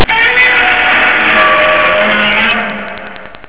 ODGŁOSY GODZILLI Z FILMU!
Roooaaaarrr!!!